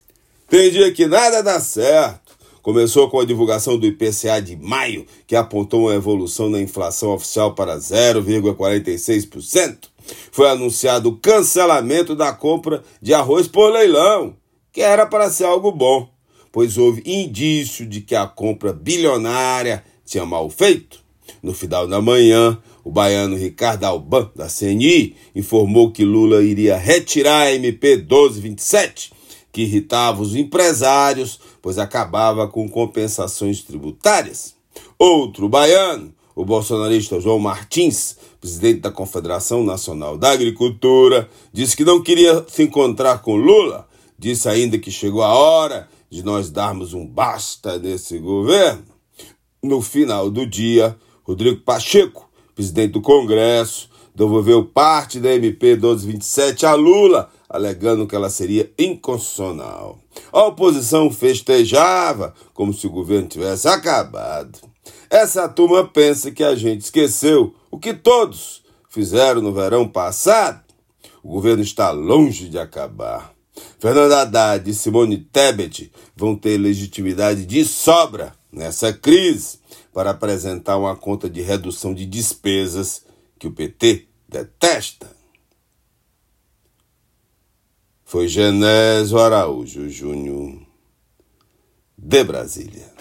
Comentário desta quarta-feira